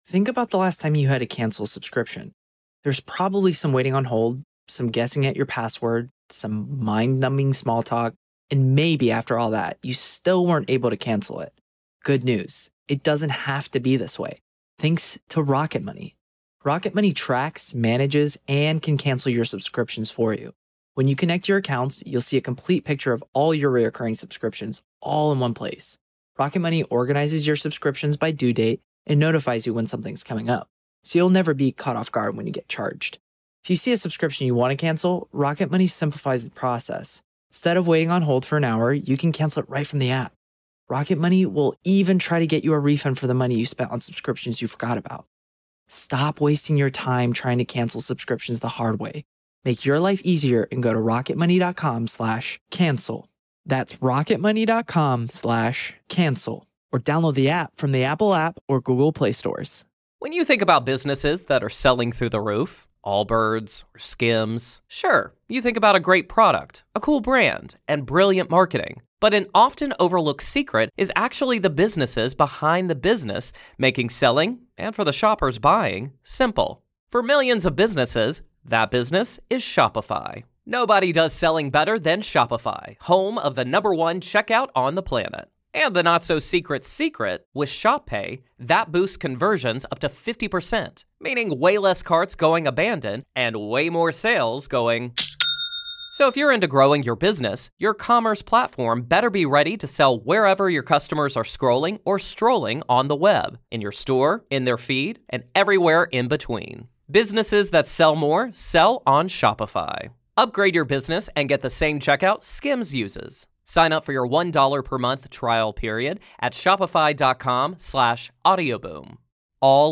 The Mona Charen Show is a weekly, one-on-one discussion that goes in depth on political and cultural topics.